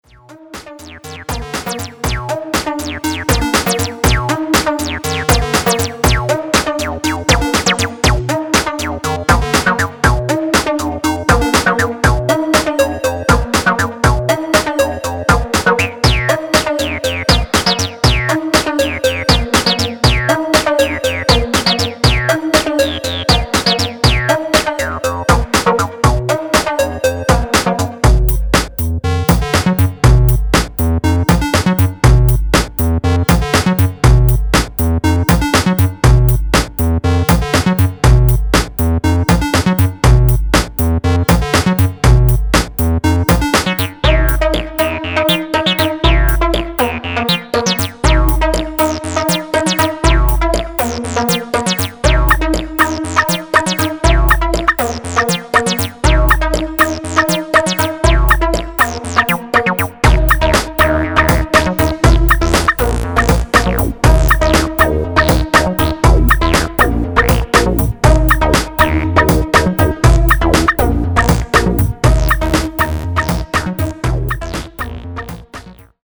それぞれが独自の捻りを効かせた ACID を4曲収録。